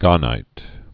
(gänīt)